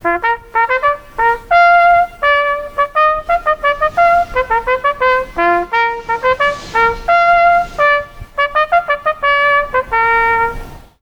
Art Festiwal przy dźwiękach trąbki
Stargard od samego rana żyje muzyką i kulturą, a to dzięki zorganizowanemu po raz pierwszy Art Festiwalowi.
dzwięk trąbka.mp3